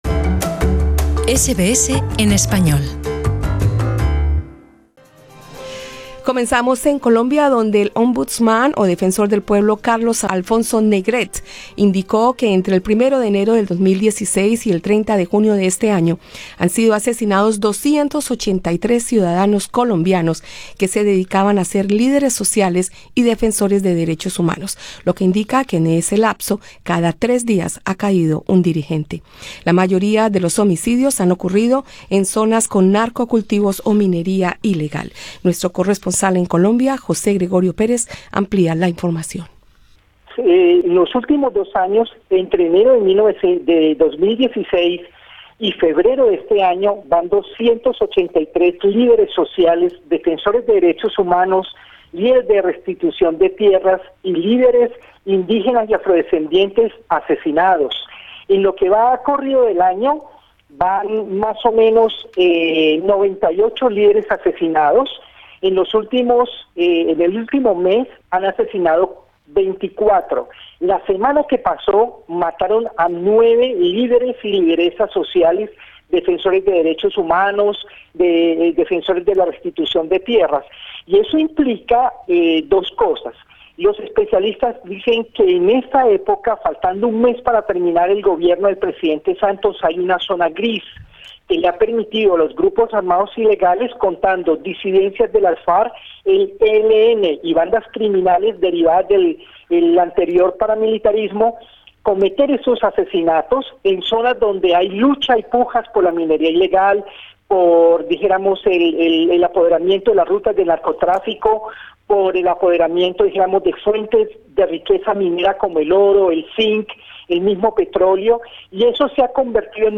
Según la Defensoría del Pueblo en Colombia, la expansion de la guerrilla del ELN, la conformación de disidencias de las FARC y la consolidación de grupos paramilitares, son los tres factores responsables. Análisis.